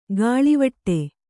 ♪ gāḷivaṭṭe